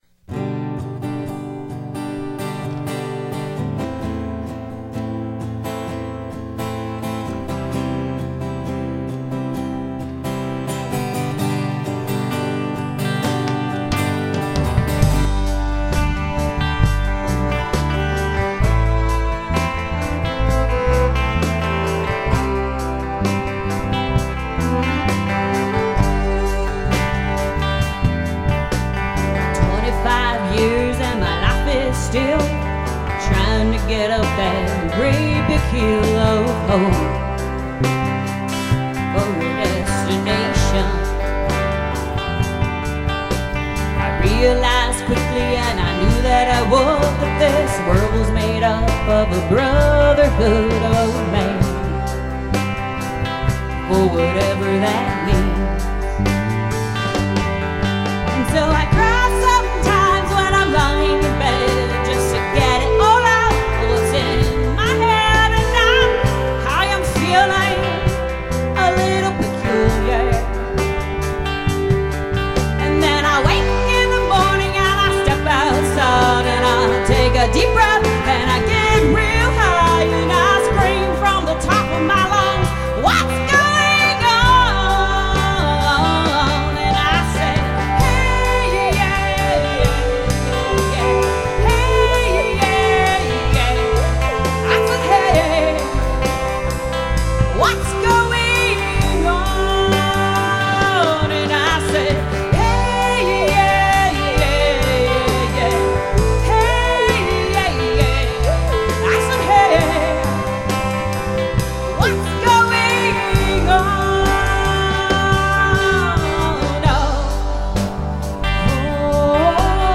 first studio album
Strong acoustic guitar chords